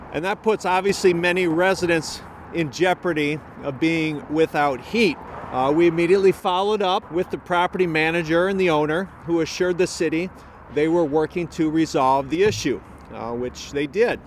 Mayor Tenhaken says the city can’t take action in cases like that until after a utility is shut off.  He called this morning’s news conference to assure tenants that the city is not ignoring complaints.